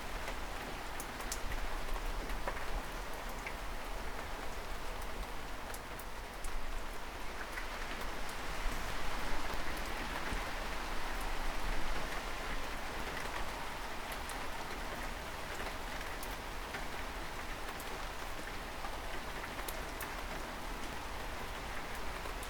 OceanBackground.wav